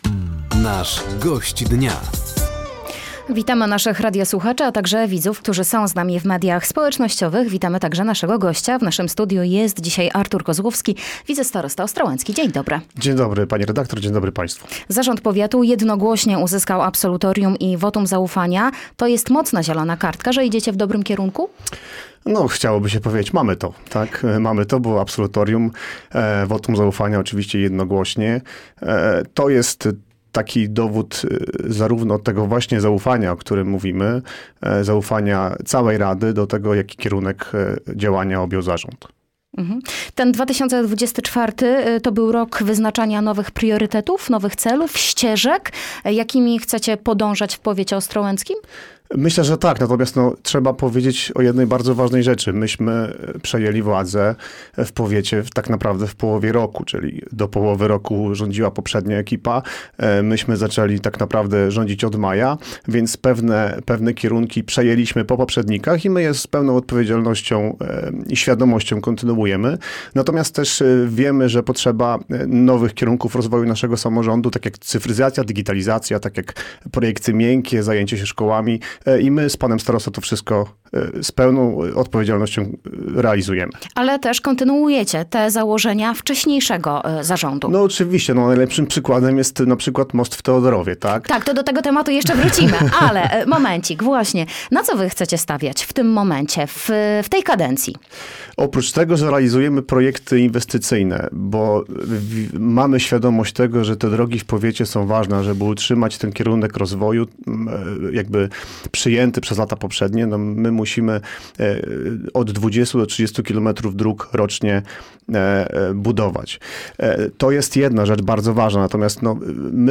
O sprawach ważnych dla mieszkańców regionu mówił wicestarosta ostrołęcki, Artur Kozłowski.